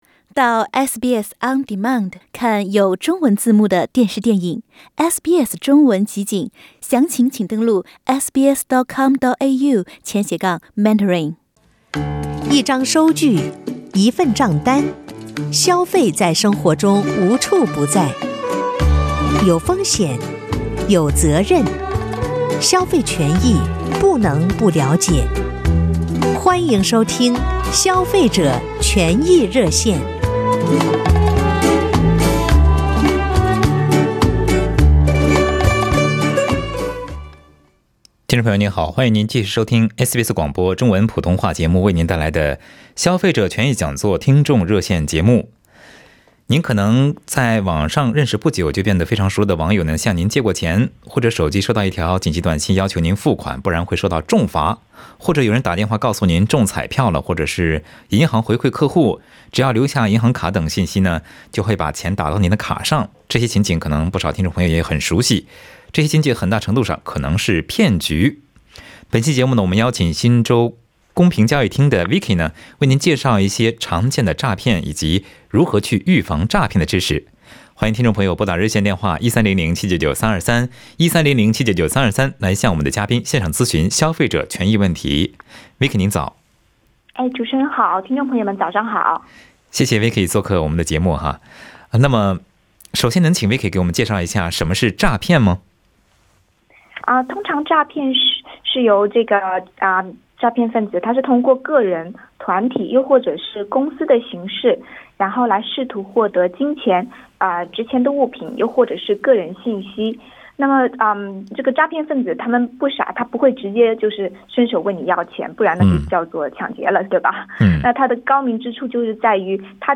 热线节目汇总